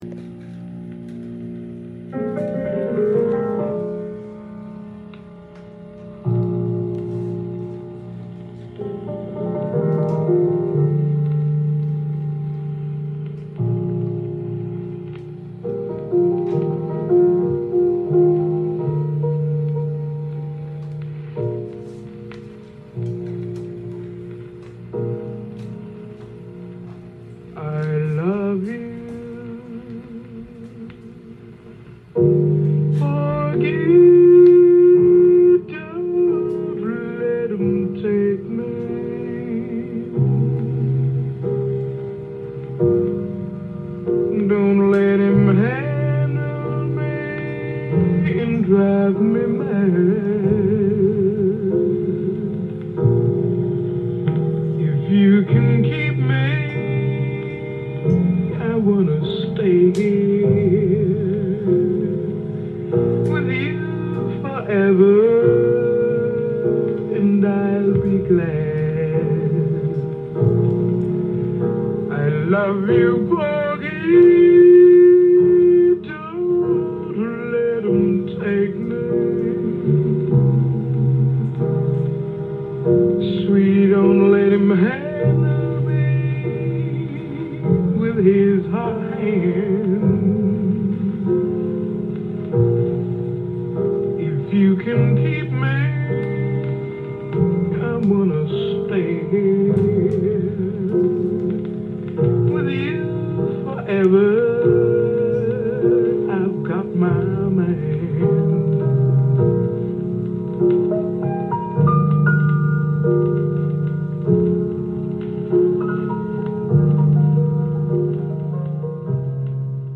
ジャンル：JAZZ-ALL
店頭で録音した音源の為、多少の外部音や音質の悪さはございますが、サンプルとしてご視聴ください。
インサート無し　盤は良好だがレコーディング時のシュー音有